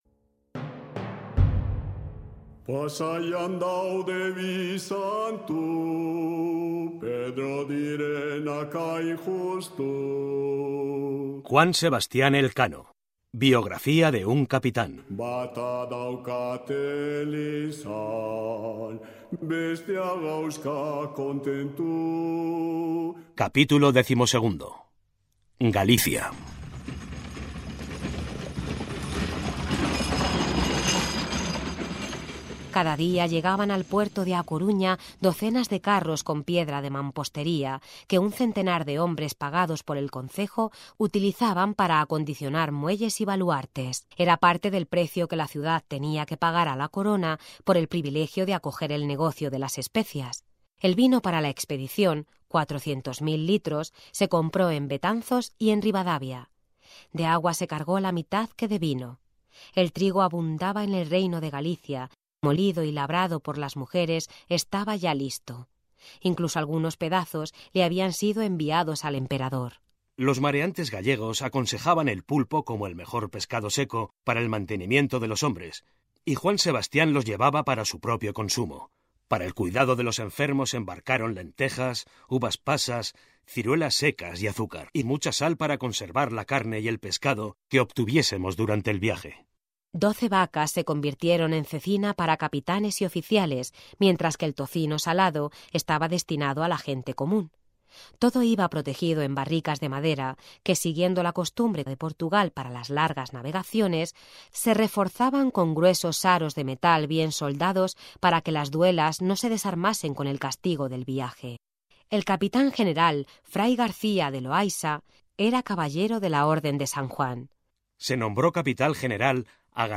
Audiolibro: Elkano biografía de un cápitan capítulo 12